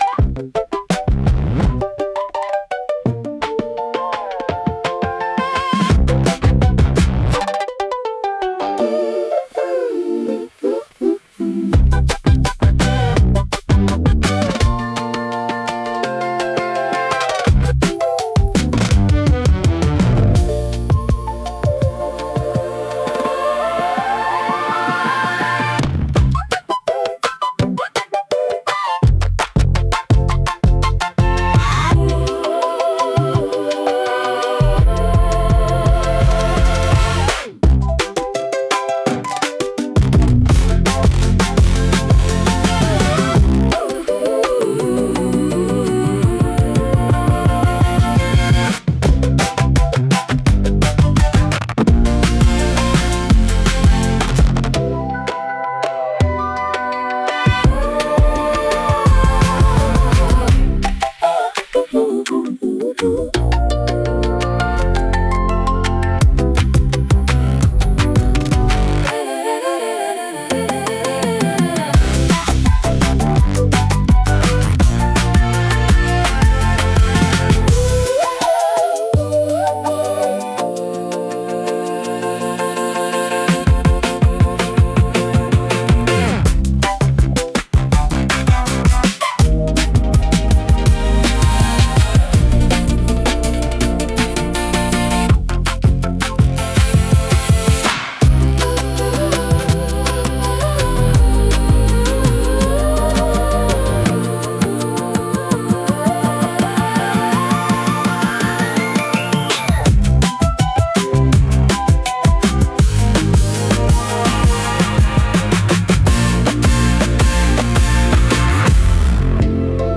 イメージ：インスト,エレクトロ・カーニバル,ローファイ・アートポップ
インストゥルメンタル（instrumental）